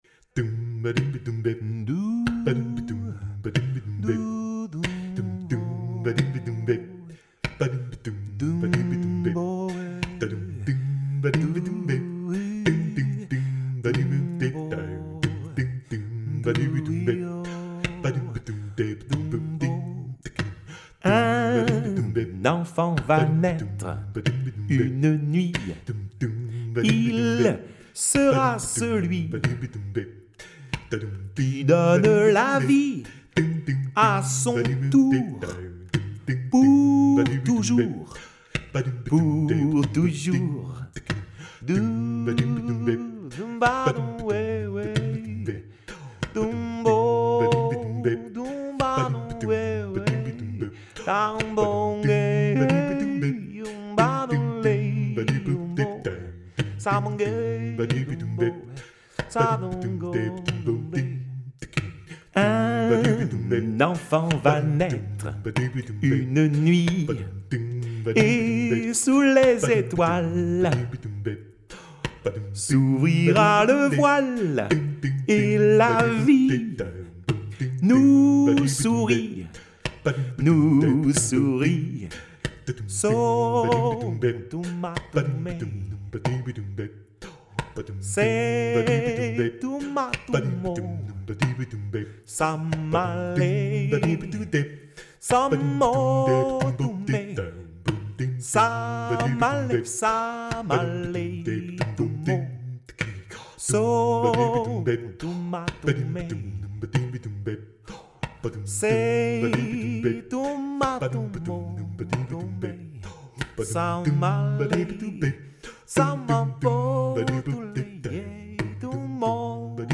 Conte musical biblique sur Noël
Les chansons en direct
voix/percussions corporelles